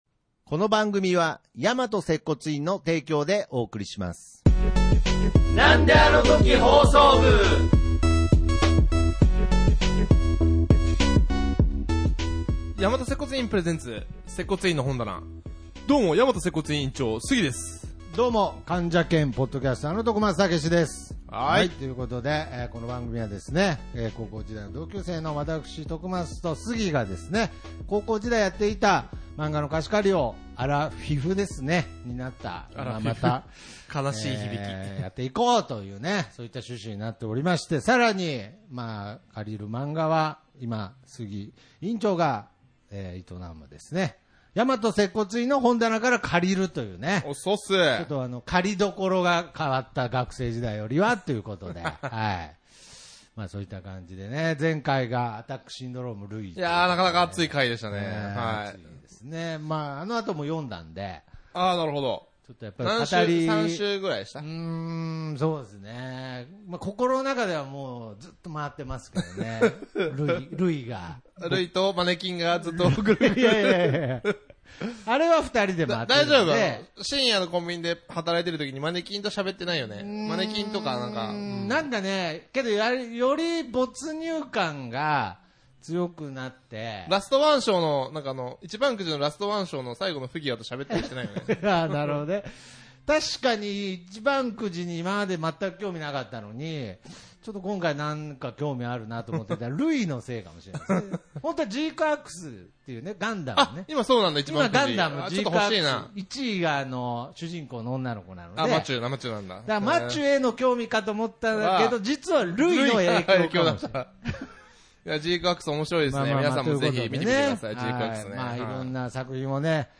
なんであのとき放送局は同級生の中年三人でやっている放送局ごっこ遊びです。